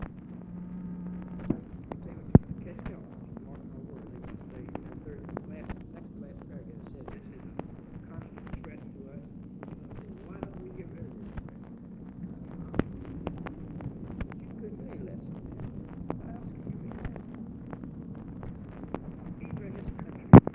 OFFICE CONVERSATION, February 7, 1964
Secret White House Tapes | Lyndon B. Johnson Presidency